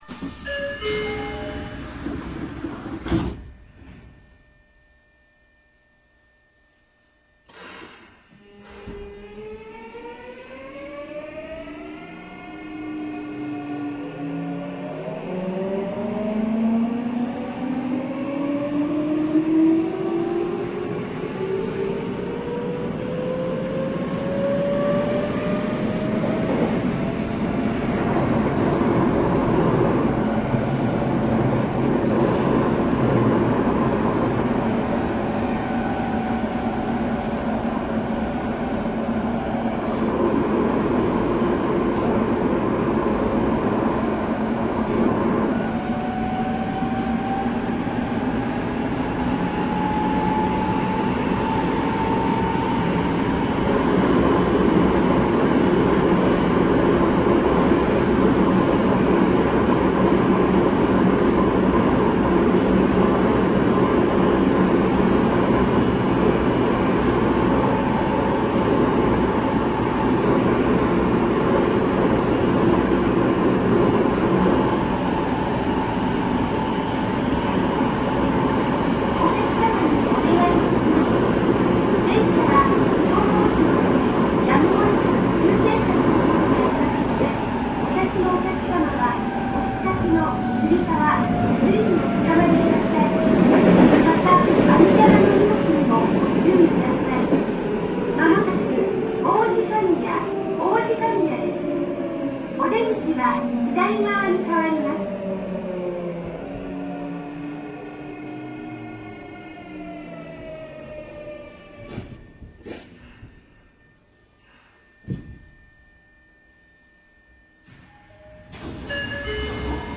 走行音(三菱GTOその2)[sub90gmb.ra/RealAudio3.0-28.8 Mono, full response/242KB]
録音区間：南北線志茂→王子神谷
種類：VVVFインバータ制御(三菱GTO第2世代Bタイプ、1C4M×2群/2両)